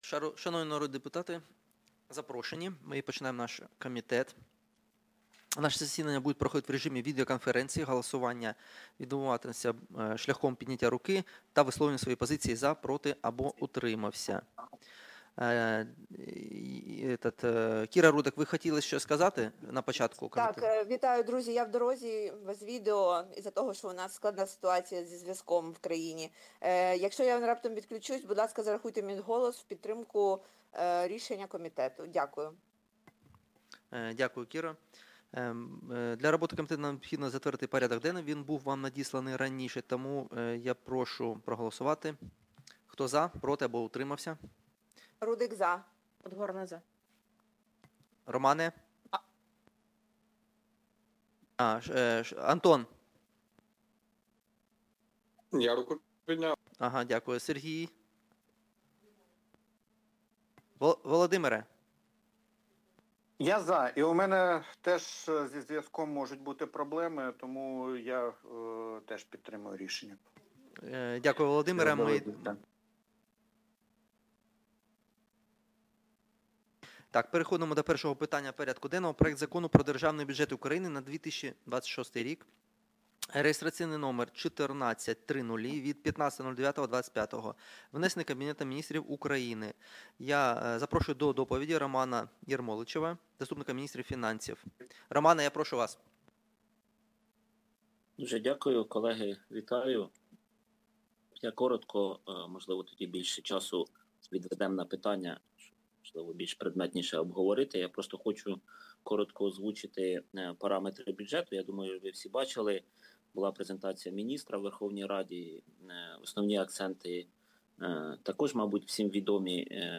Аудіозапис засідання Комітету від 30.09.2025